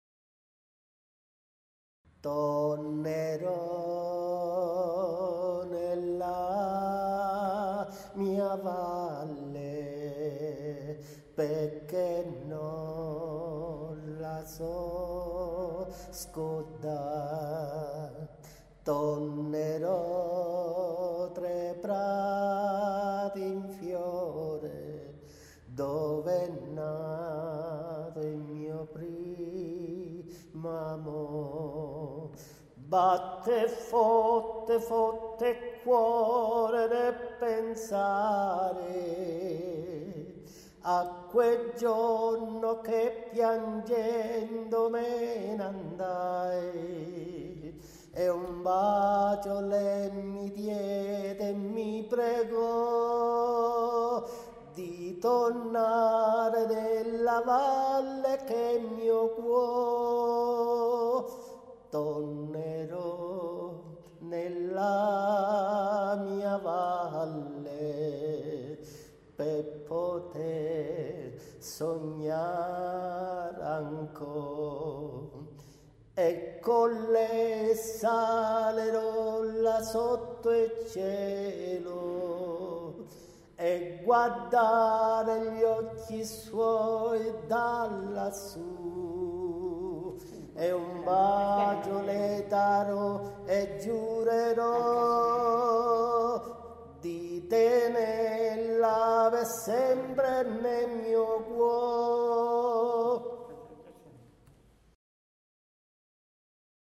SERENATE E FILASTROCCHE
Serenata